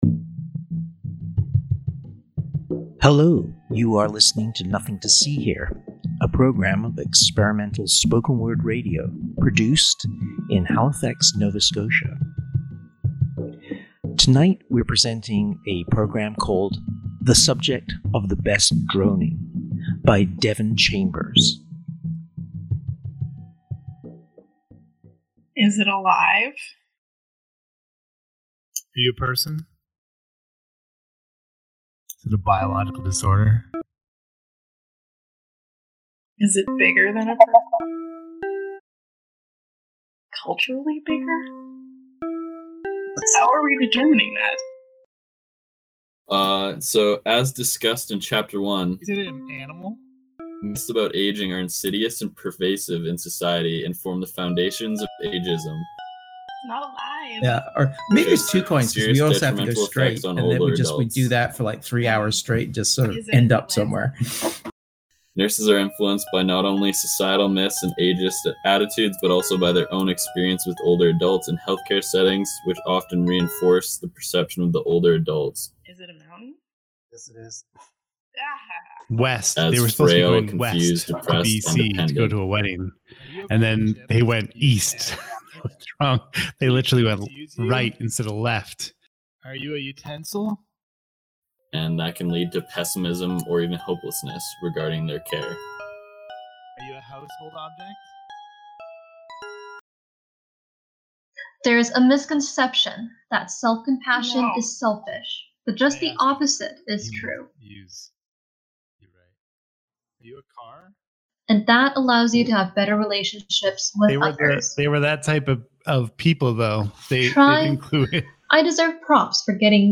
Experimental audio